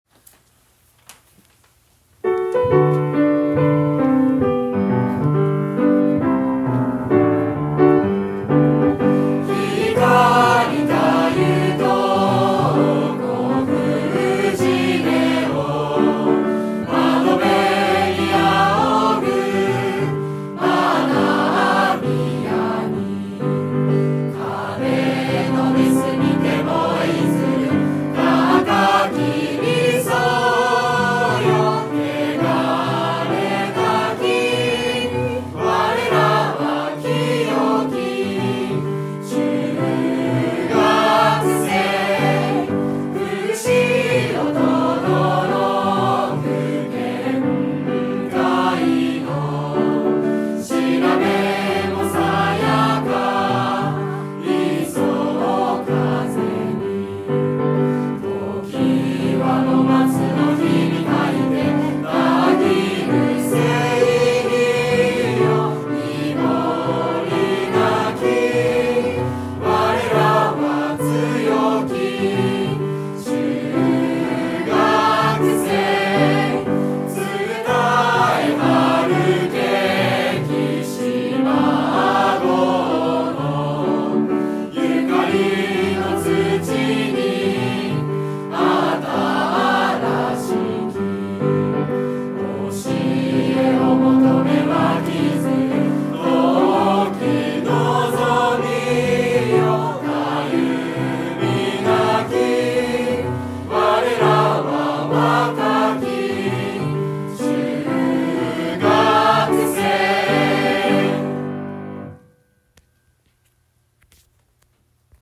校歌（音声）